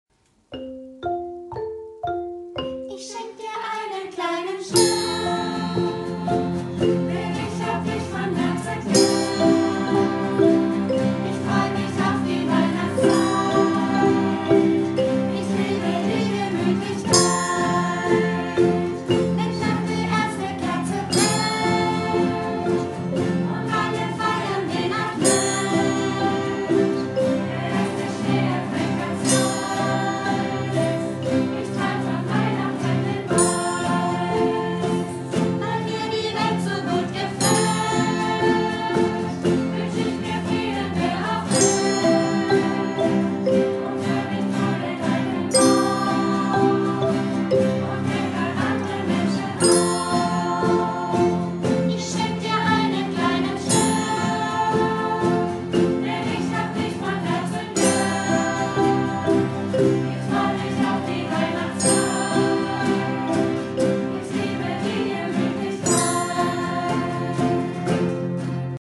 in der einfachen Version mit nur zwei 🎸🎹 Akkorden und mit vier Tönen 🎶🎵. Ein kleiner Ausschnitt aus einer Fortbildung
💫🌠 Zum Einsatz kamen C-Gitarre, Triangel, Klangbausteine & Xylophon 🎸🎹🥁.